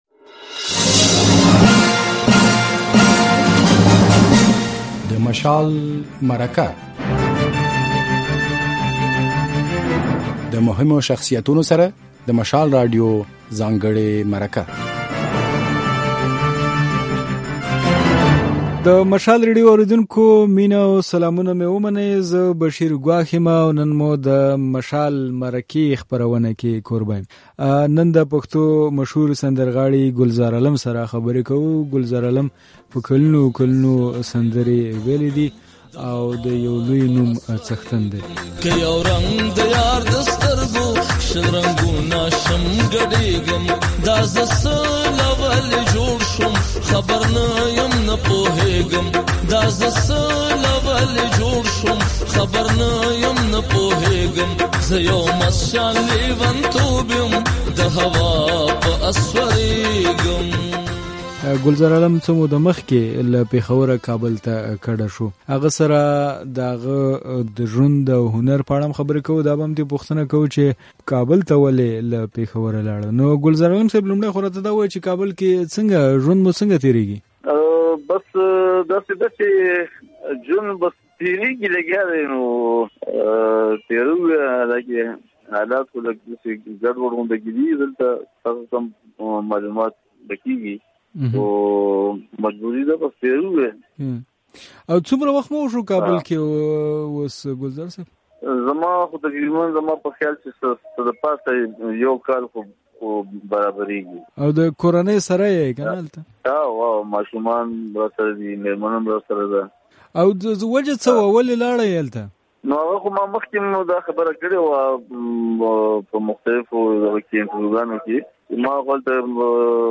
ګلزار عالم سره مرکه دلته واورئ